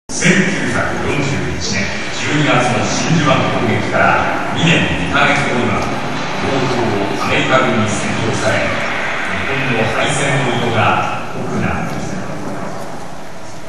ジ ョ イ ン ト リ サ イ タ ル
平成十九年八月二十六日 (日)　於：尼崎アルカイックホール